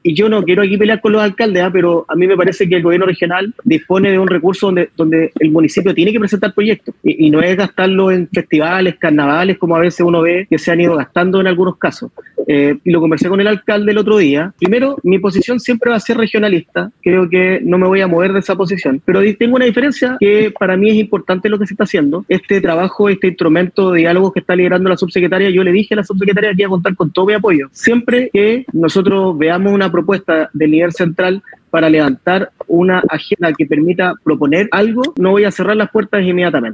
El gobernador de Tarapacá, José Miguel Carvajal, en conversación con Radio Paulina, abordó los diálogos que la Subsecretaría de Hacienda está llevando a cabo sobre la renovación de la concesión de la Zona Franca de Iquique (ZOFRI).